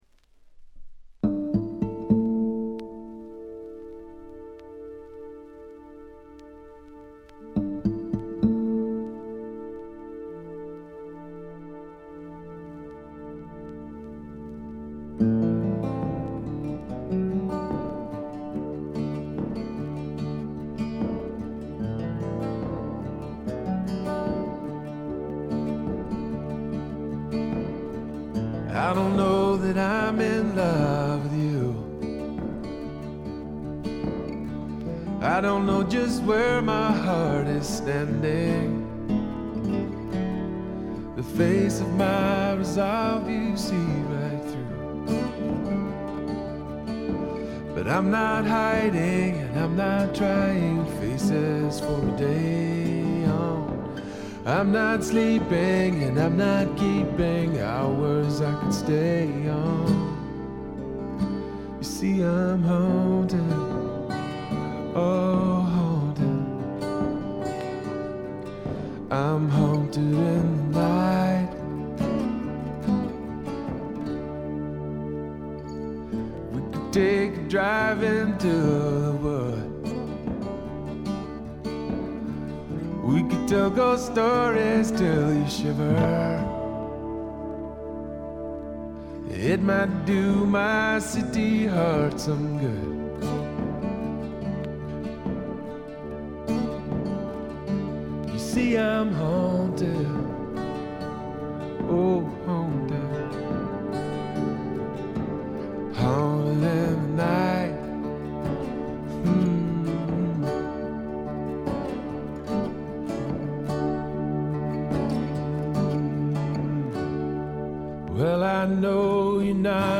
試聴曲は現品からの取り込み音源です。
Recorded At - Rex Recording, Portland